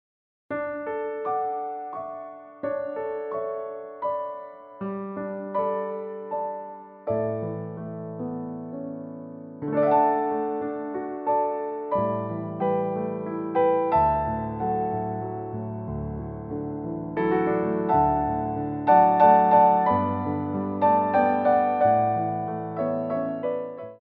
Ports de Bras
3/4 (8x8)